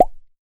spinnerspin.wav